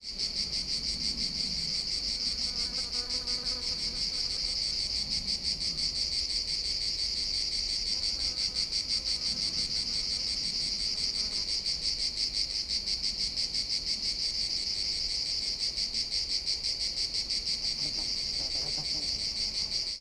cicadas_mono.wav